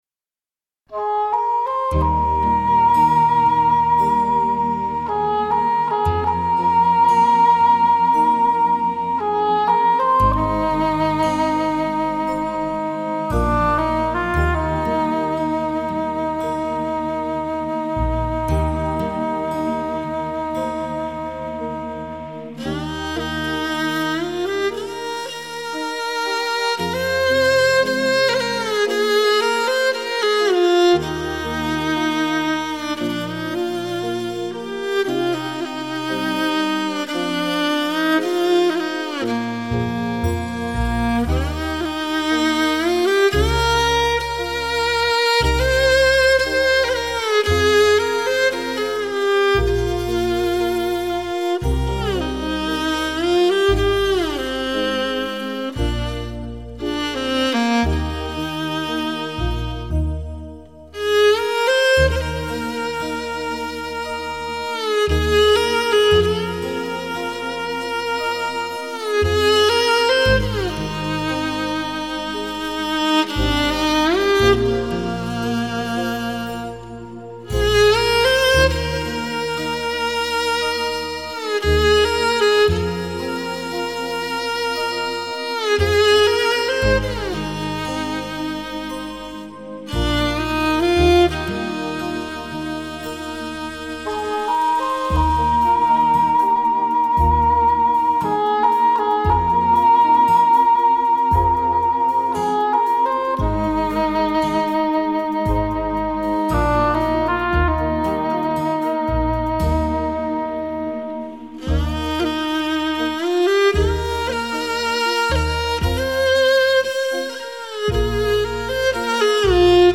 马头琴所演奏的乐曲，具有深沉粗犷，激昂的特点，体现了蒙古民族的生产、生活和草原风格。